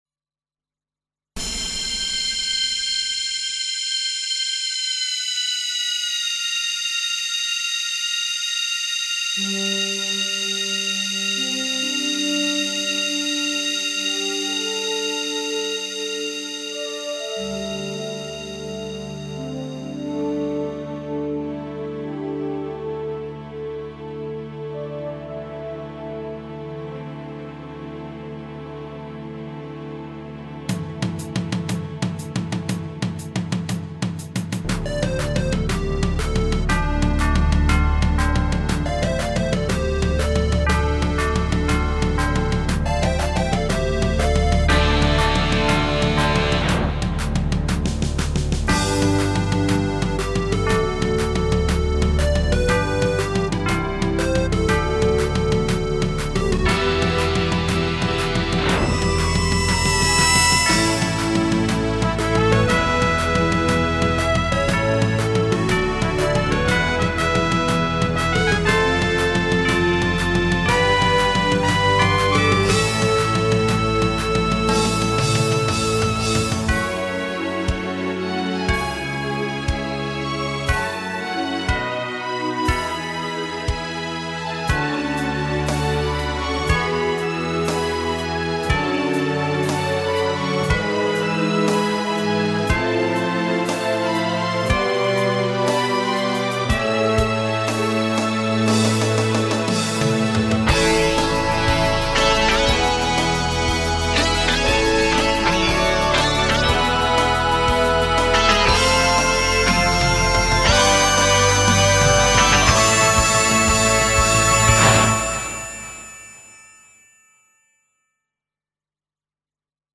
Midi/GEMS-style remix with a building crescendo: